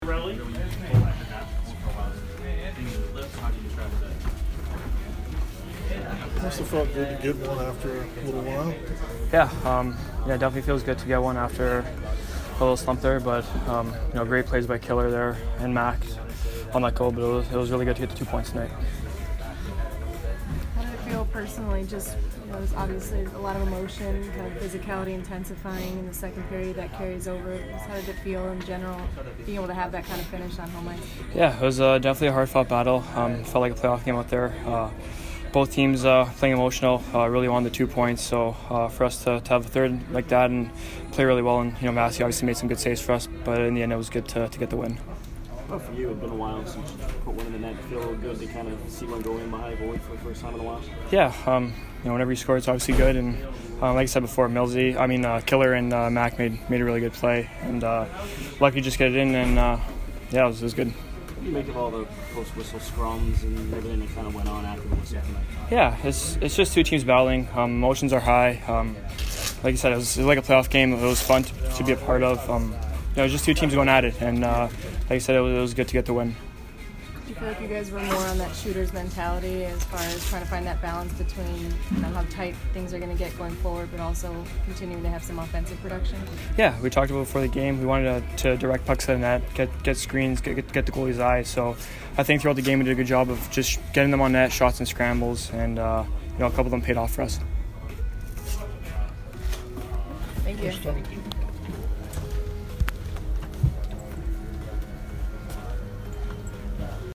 Anthony Cirelli post-game 2/9